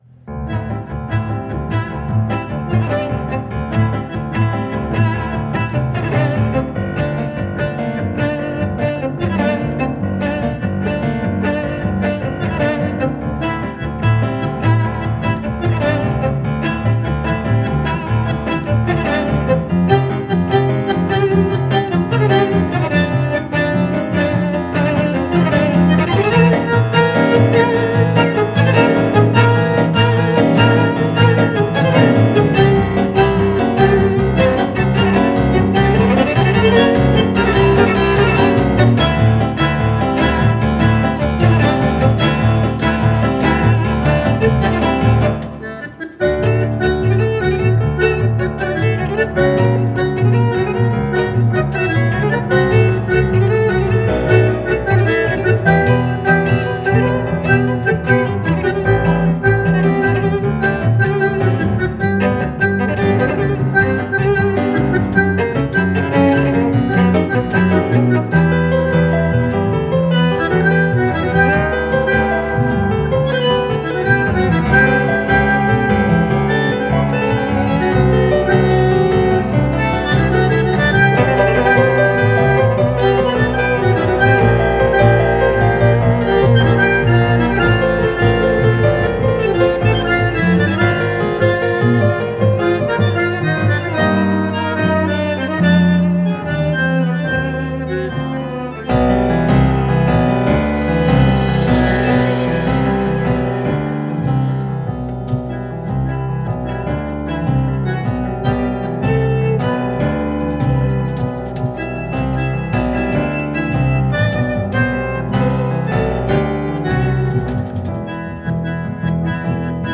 Танго
live